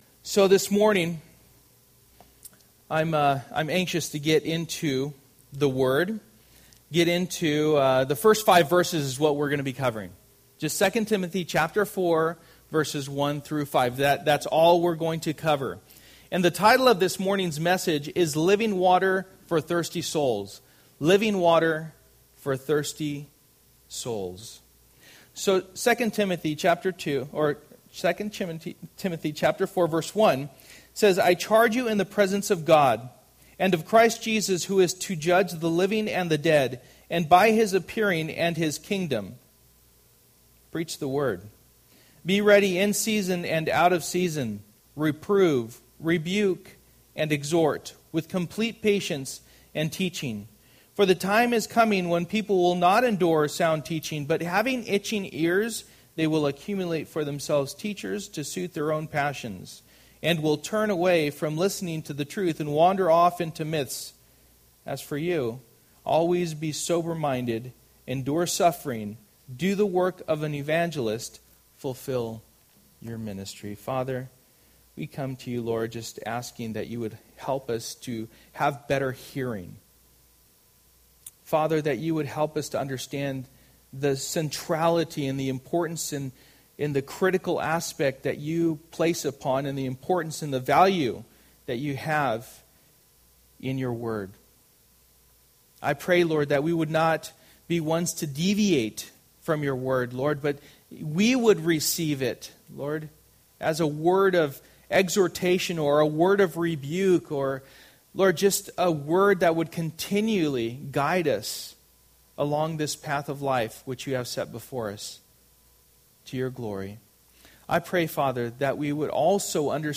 Model & Guard Passage: 2 Timothy 4:1-5 Service: Sunday Morning %todo_render% « Dad’s Best Gift Power of the Holy Spirit